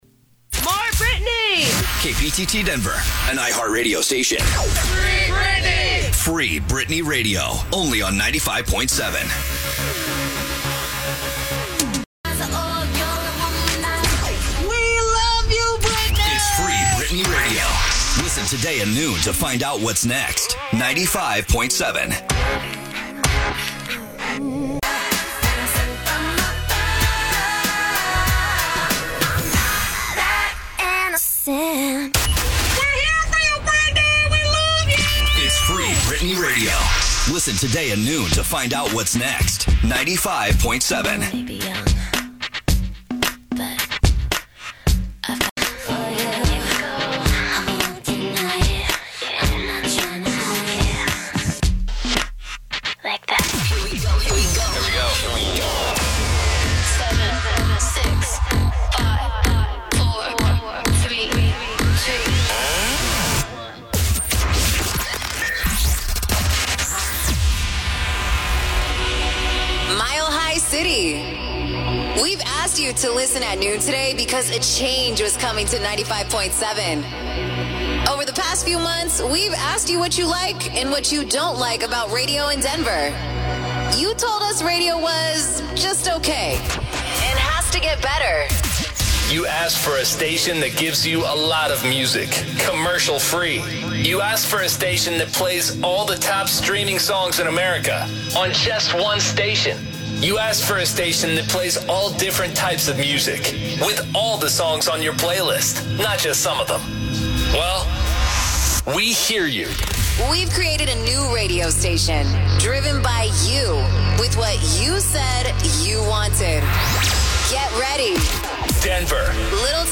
Previous Format: CHR “95.7 The Party” (Stunting As “Free Britney Radio“)
New Format: CHR “Hits 95.7